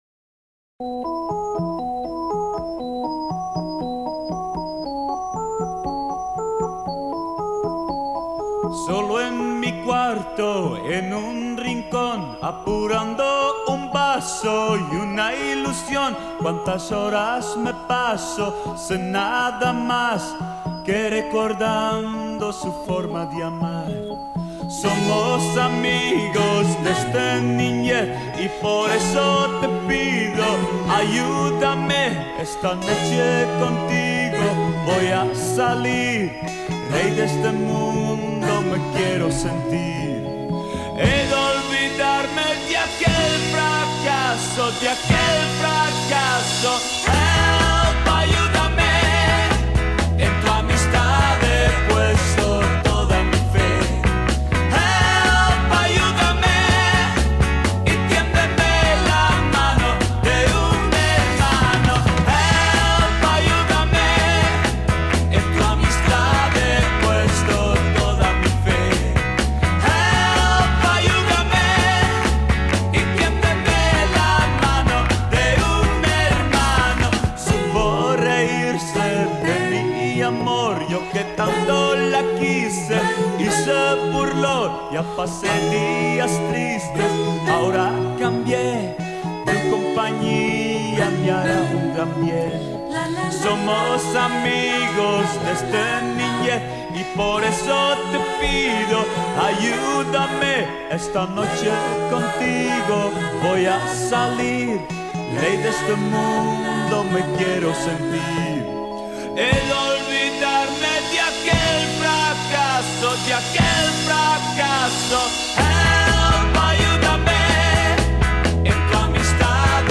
En el recuerdo y homenaje al cantante y músico holandés